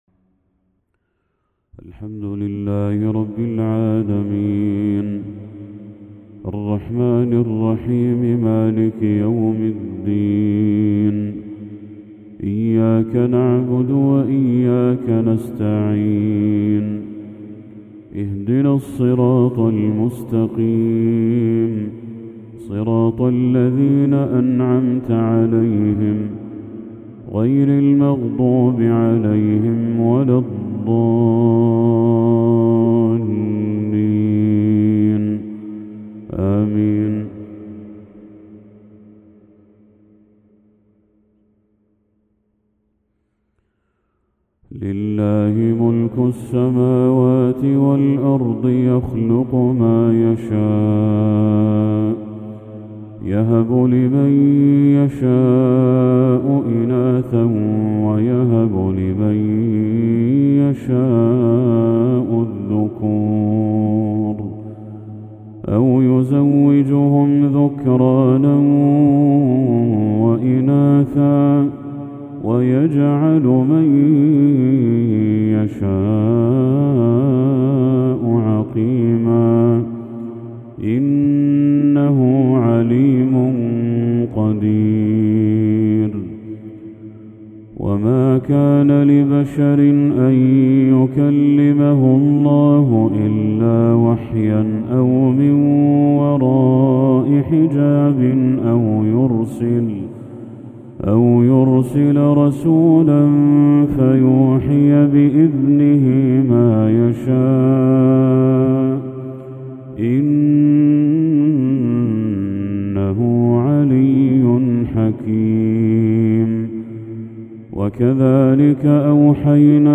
تلاوة لخواتيم سورتي الشورى والأحقاف
مغرب 19 ذو الحجة 1445هـ